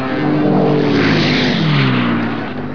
Som dos motores de um P-38 em vôo rasante.